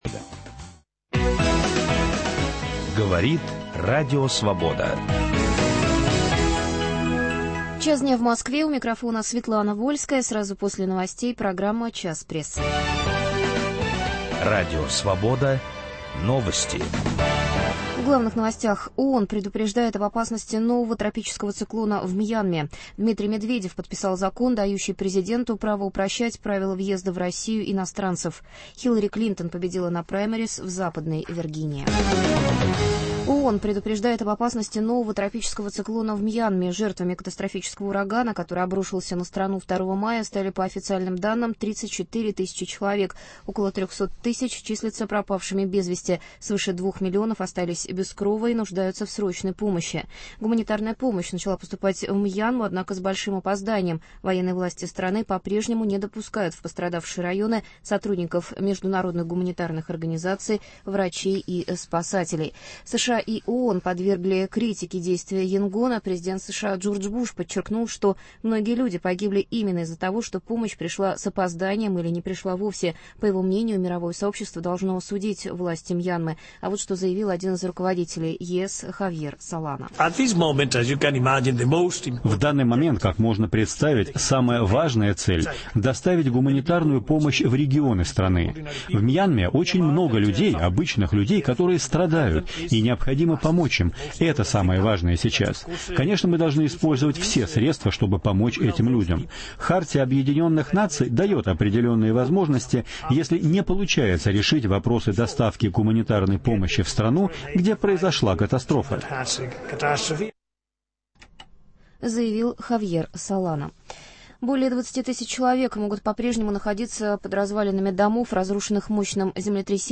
В программе участвуют журналисты